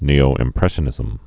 (nēō-ĭm-prĕshə-nĭzəm)